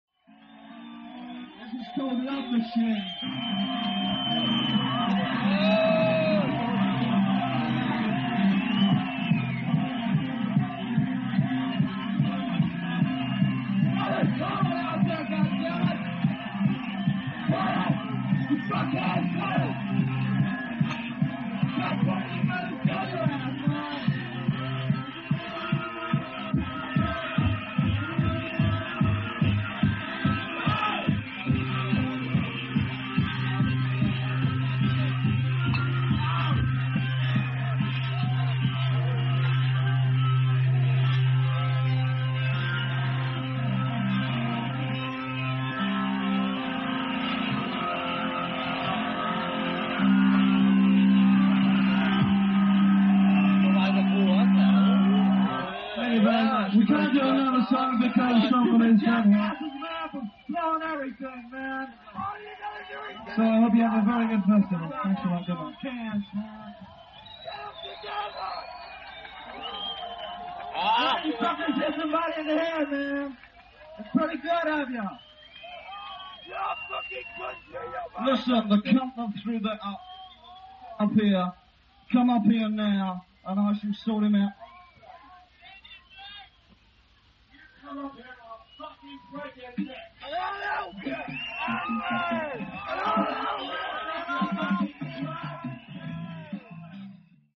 Venue:  Open Air Festival
Sound:  Remastered
Source:  Audience Recording